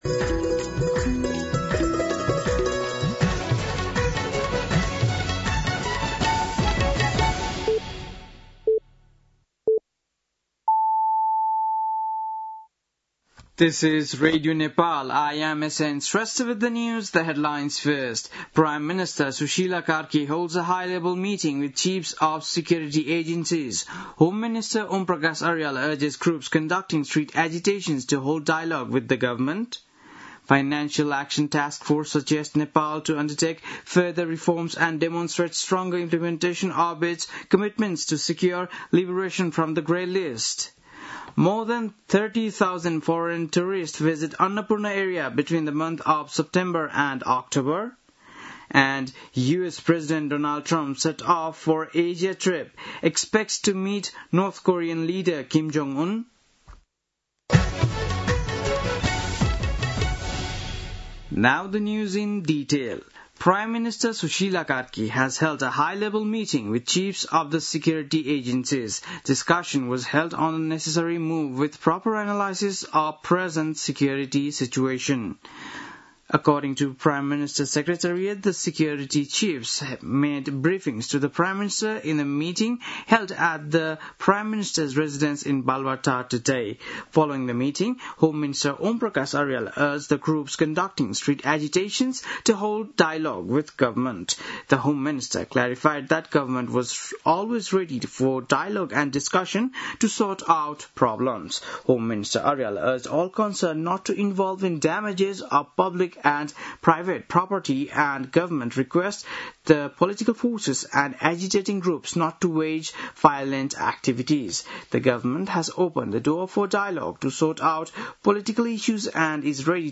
बेलुकी ८ बजेको अङ्ग्रेजी समाचार : ८ कार्तिक , २०८२